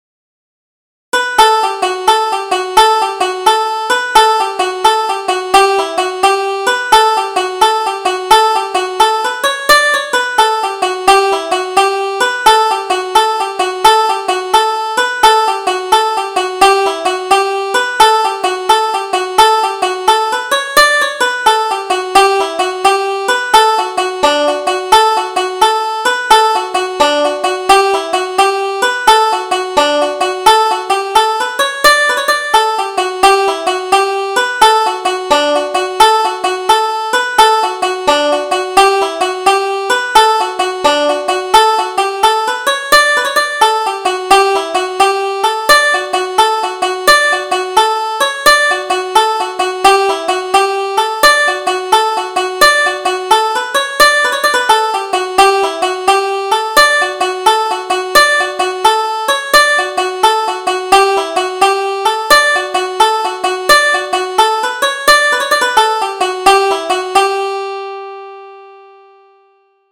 Double Jig: The Little House under the Hill